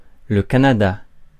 Ääntäminen
Ääntäminen France (Paris): IPA: [lœ ka.na.da] Tuntematon aksentti: IPA: /ka.na.da/ Haettu sana löytyi näillä lähdekielillä: ranska Käännös 1.